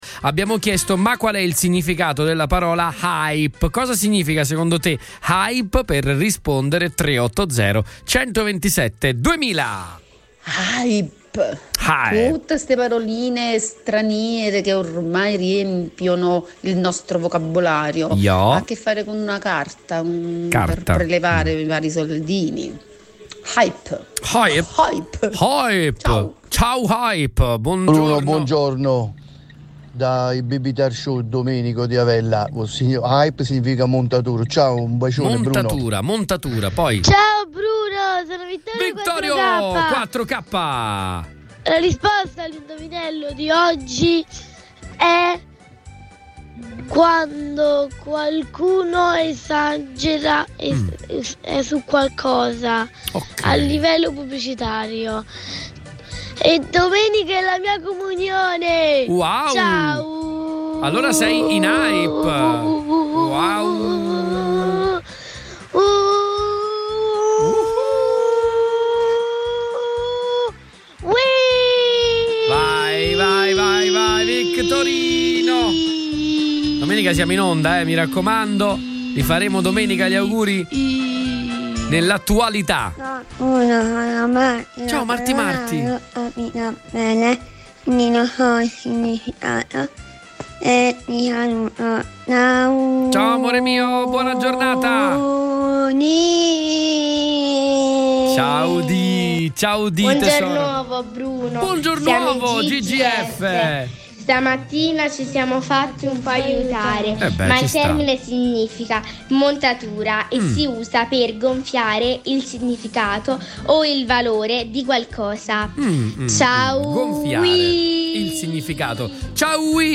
RIASCOLTA DA QUI LE RISPOSTE DEGLI ASCOLTATORI